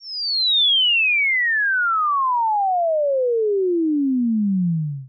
Cartoon Falling Whistle
cartoon descend fall whistle sound effect free sound royalty free Movies & TV